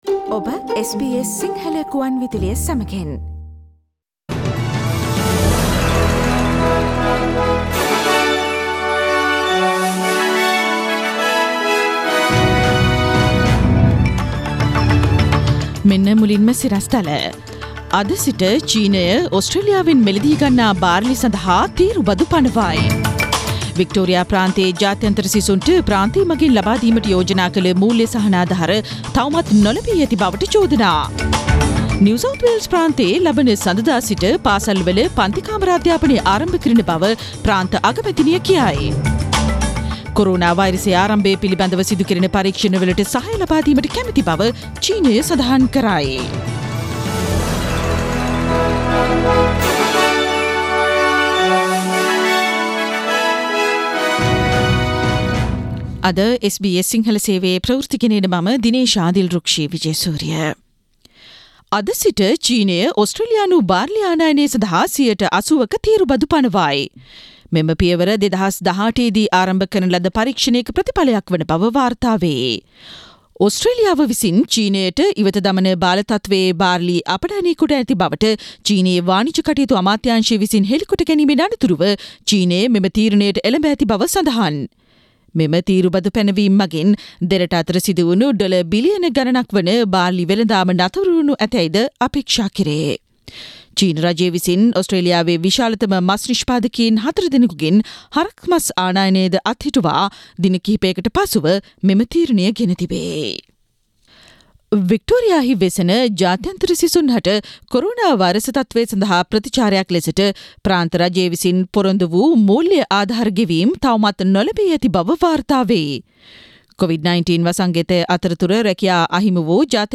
Today’s news bulletin of SBS Sinhala radio – Tuesday 19 May 2020